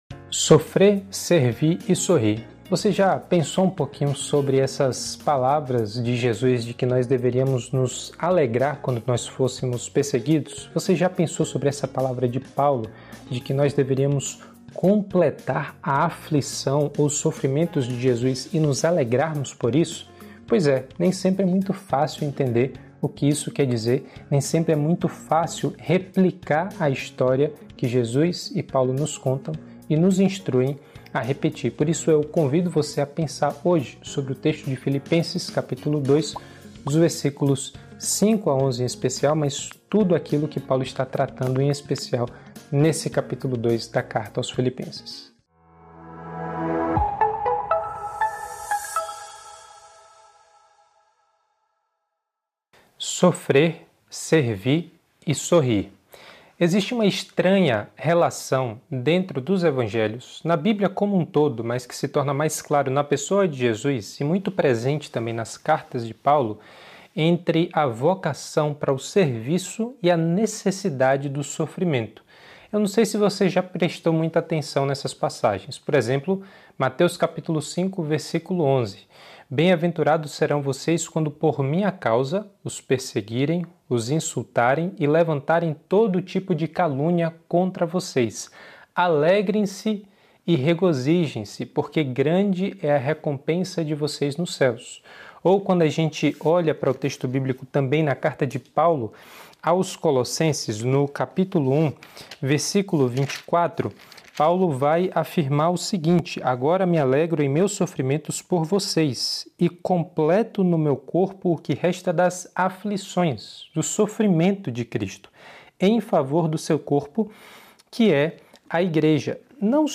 Igreja Batista Nações Unidas